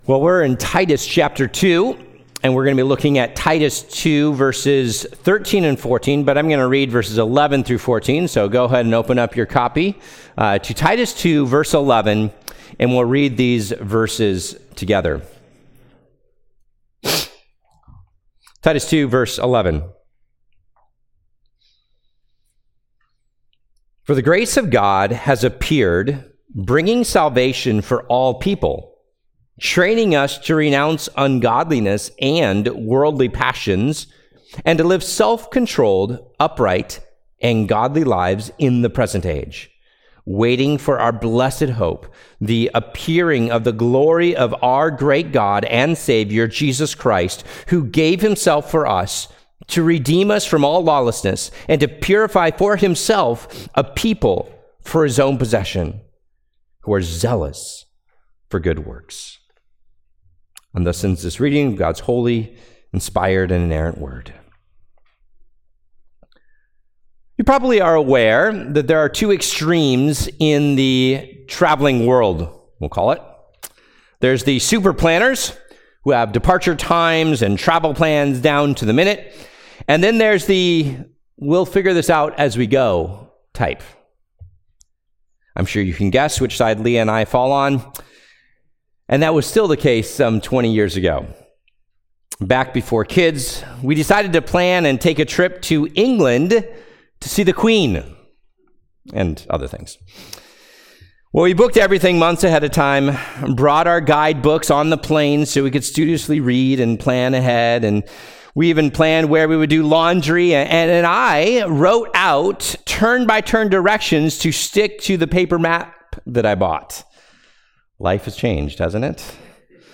Sermons - First Baptist Church of Farmington